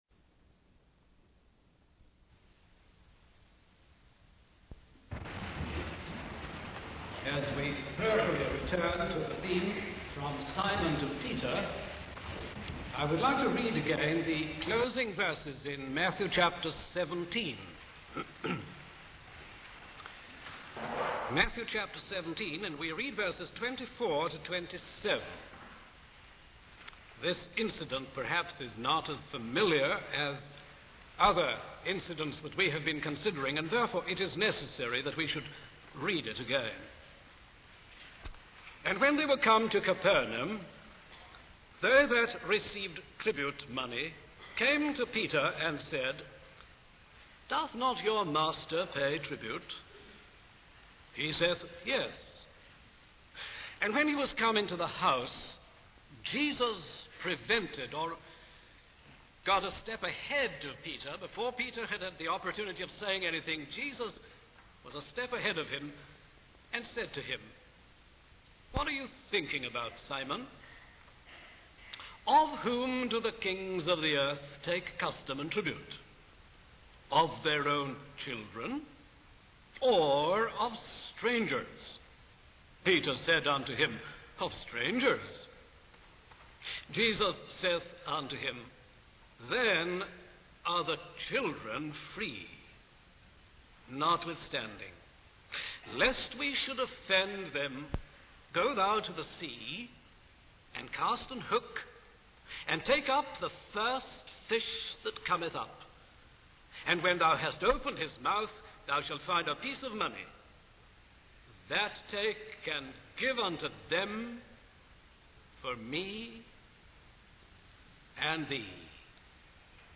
In this sermon, the speaker focuses on a seemingly insignificant incident in the life of Jesus and Peter. The incident involves a discussion about paying tribute money.